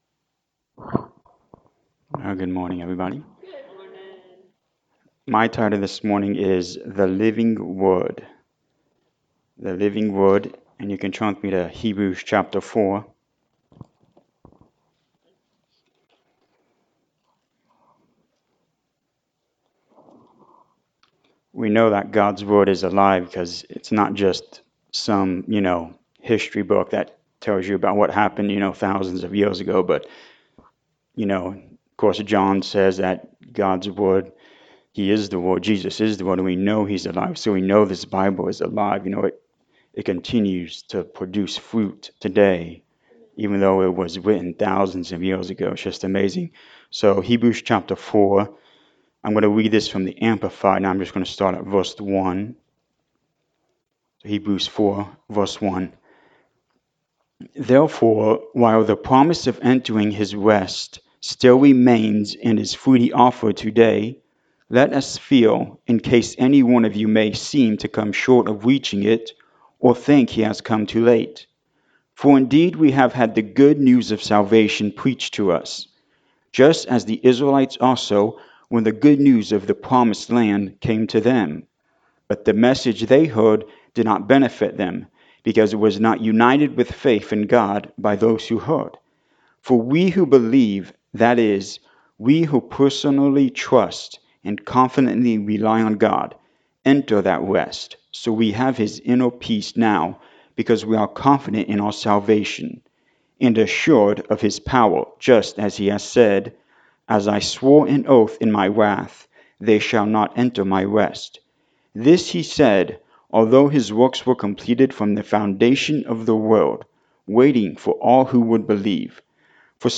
The Living Word and Hardship Series, Sermon 7 – Sickness, Disease & Infirmities
Service Type: Sunday Morning Service